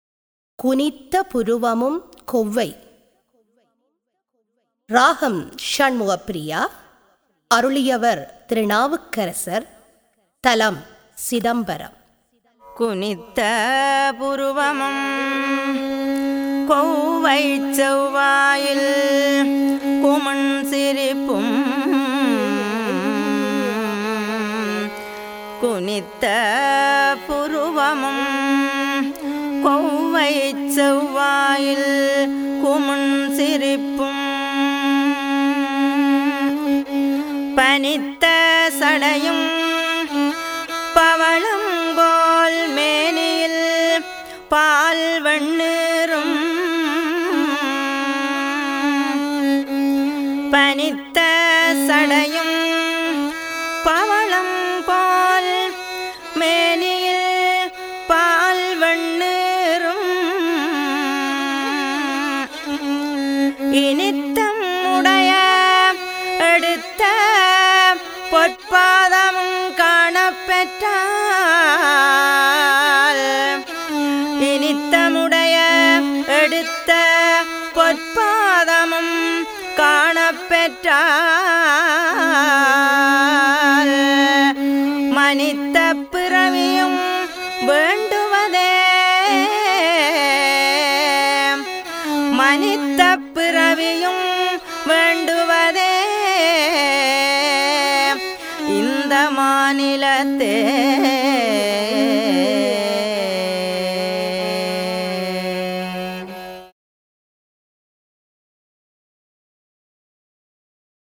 தரம் 10 - சைவநெறி - அனைத்து தேவாரங்களின் தொகுப்பு - இசைவடிவில்